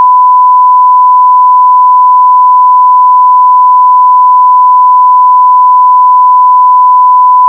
SONAR 3.1 に  1KHz のサイン波を読み込み、それぞれ各周波数に変換した後の波形を見てみました。